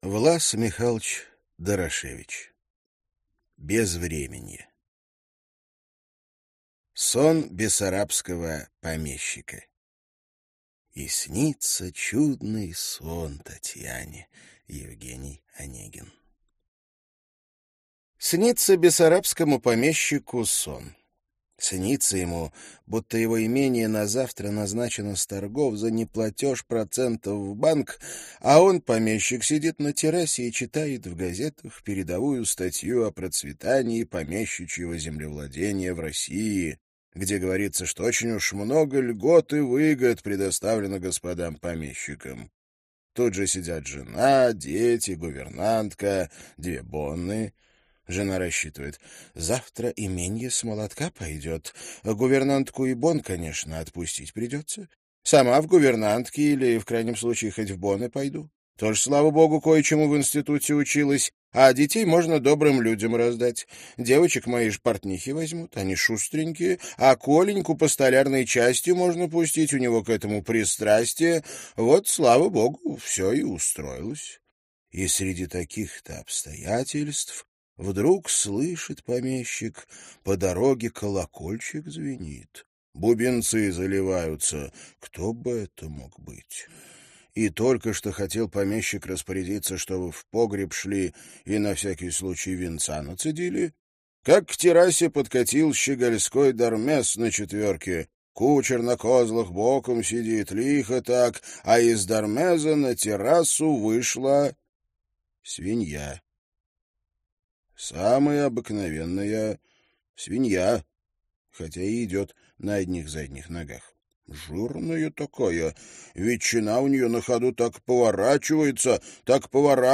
Аудиокнига Безвременье | Библиотека аудиокниг